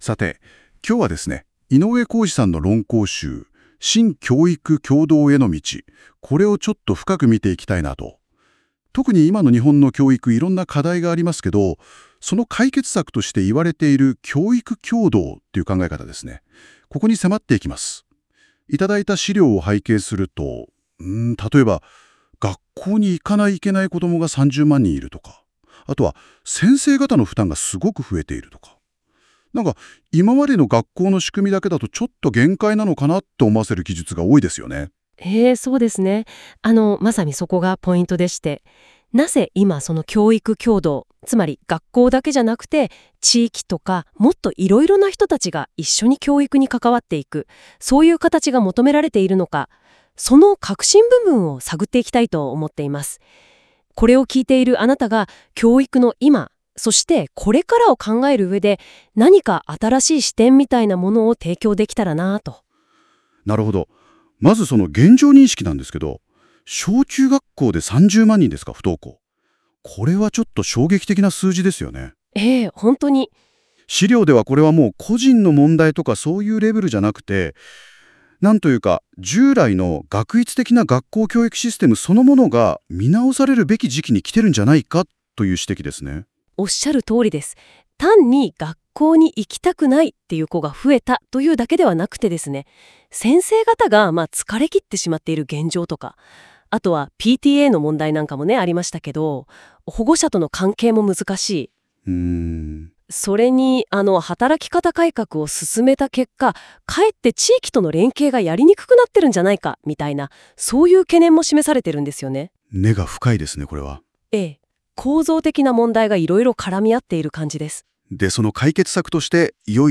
ただし、固有名詞の読み間違い等がそれなりにあり、やはり機械（AI）なのだ　なあと、独り苦笑いしています！
音声解説